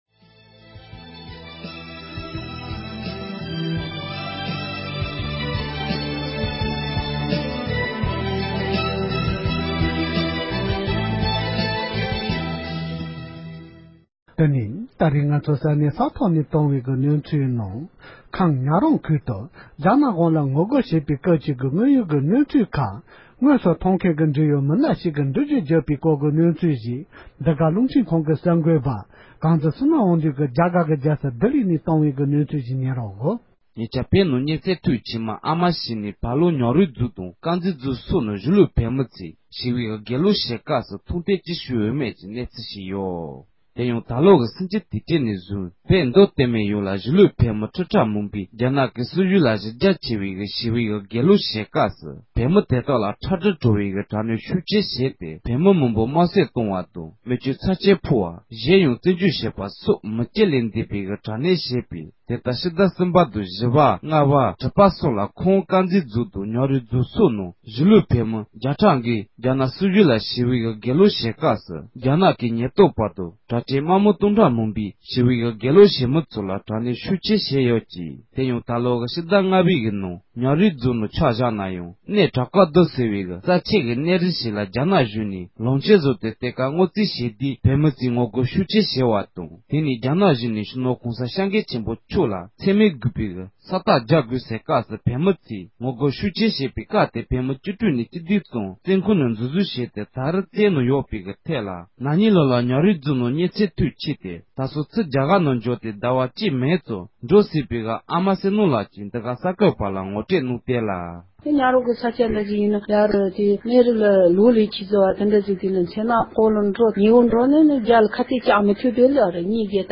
ཁམས་ཉ་རོང་ཁུལ་དུ་བོད་མི་ཚོས་རྒྱ་གཞུང་ལ་ངོ་རྒོལ་གྱི་ལས་འགུལ་སྤེལ་བའི་དངོས་ཡོད་གནས་ཚུལ་འབྲེལ་ཡོད་མི་སྣ་ཞིག་གིས་འགྲེལ་བརྗོད་གནང་བ།
སྒྲ་ལྡན་གསར་འགྱུར།